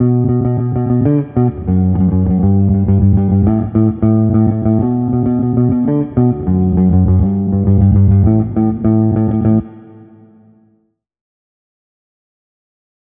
Federhall
Soundbeispiel Bass
Federhall zeichnet sich durch deutlich wahrnehmbare, diskrete Reflexionen aus.
bassampspring.wav